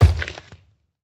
sounds / mob / zoglin / step3.ogg